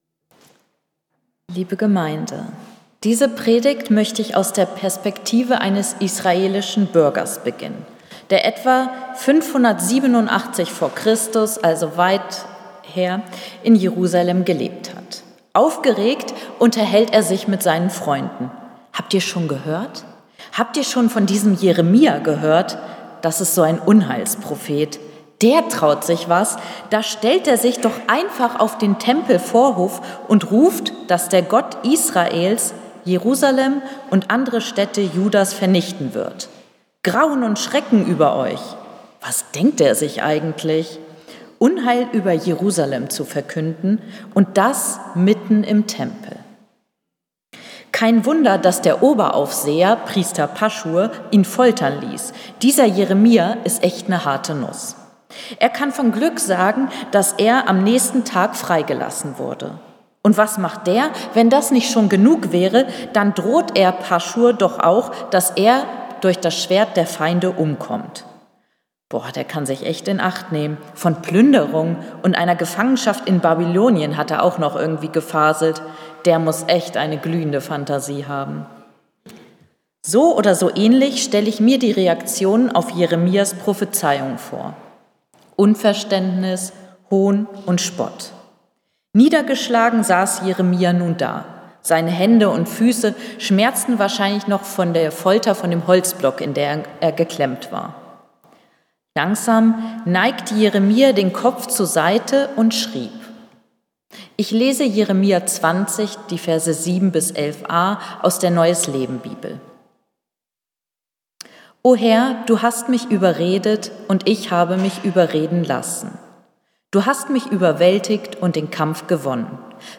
09.06.2024 Predigt | Bethel-Gemeinde Berlin Friedrichshain